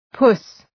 Προφορά
{pʋs}